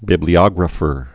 (bĭblē-ŏgrə-fər)